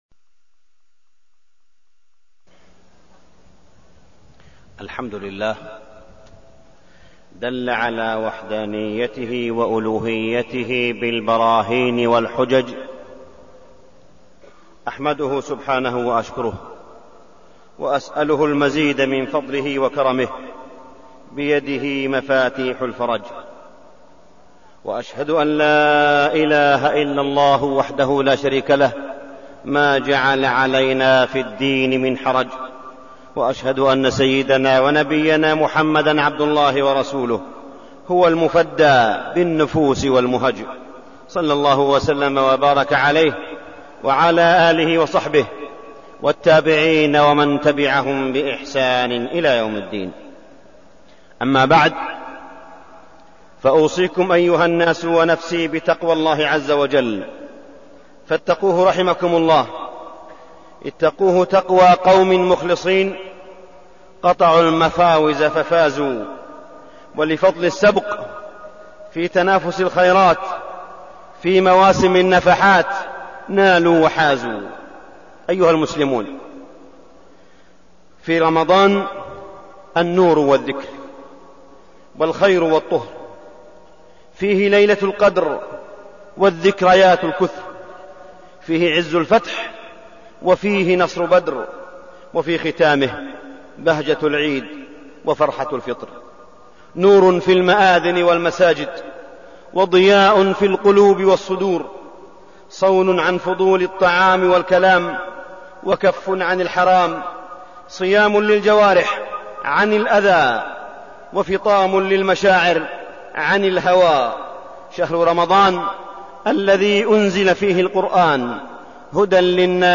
تاريخ النشر ١٥ رمضان ١٤١٧ هـ المكان: المسجد الحرام الشيخ: معالي الشيخ أ.د. صالح بن عبدالله بن حميد معالي الشيخ أ.د. صالح بن عبدالله بن حميد رمضان والفضائيات اللاهية